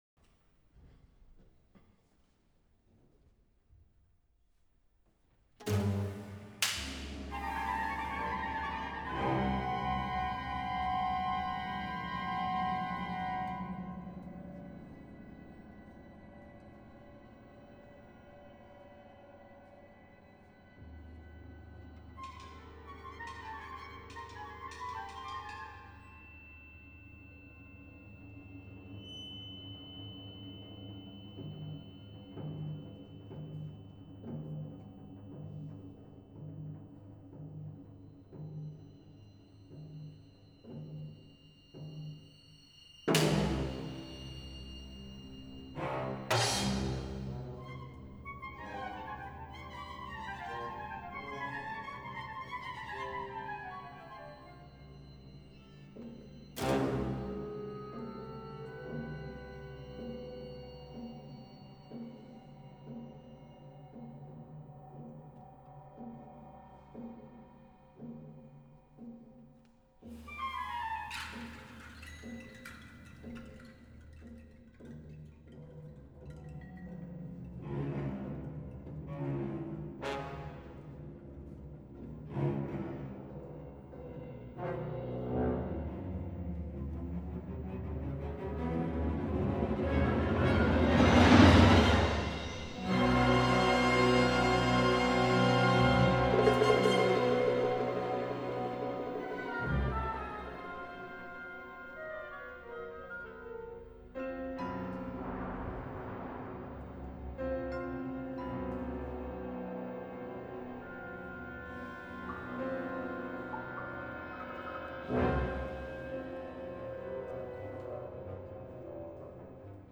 for orchestra